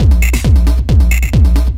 DS 135-BPM D7.wav